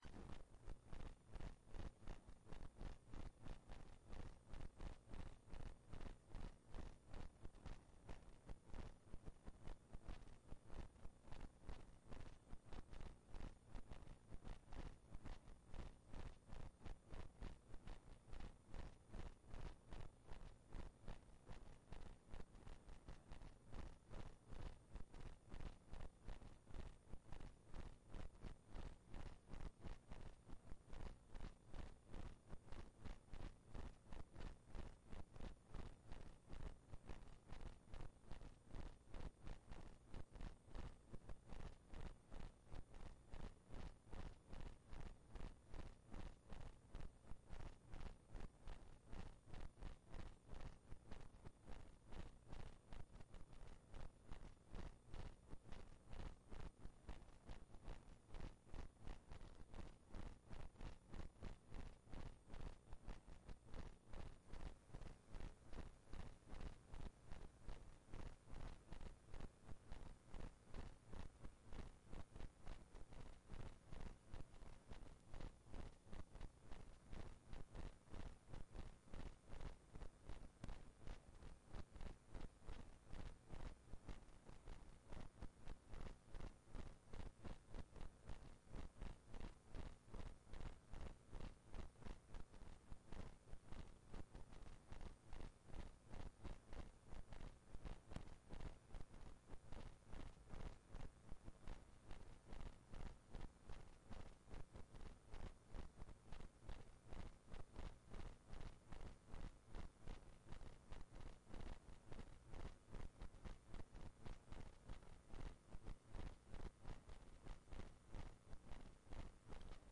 Database Security - Part 1 - NTNU Forelesninger på nett